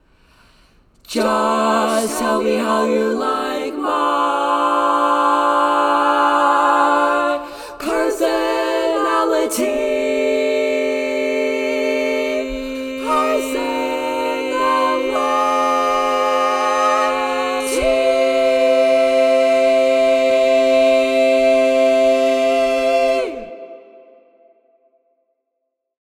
How many parts: 4
Type: Barbershop
All Parts mix:
Learning tracks sung by